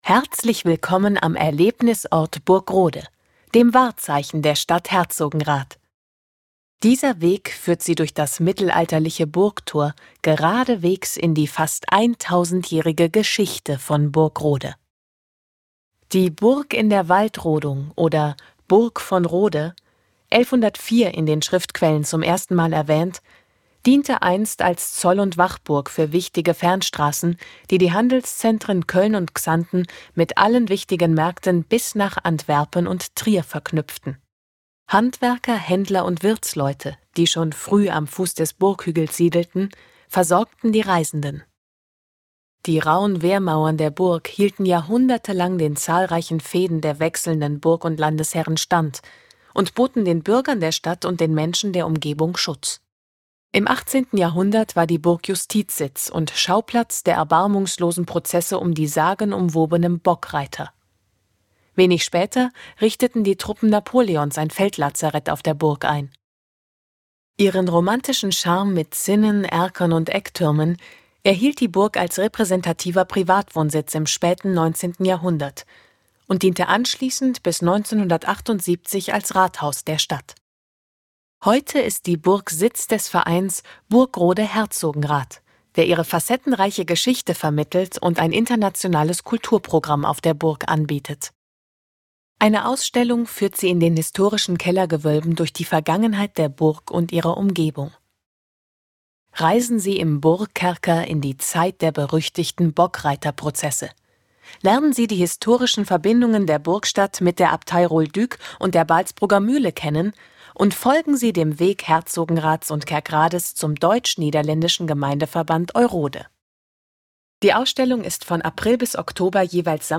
Die Tafeln sind mit Audioguides ausgestattet, so dass sich auch die Inhalte der Tafeln akustisch wiedergeben lassen.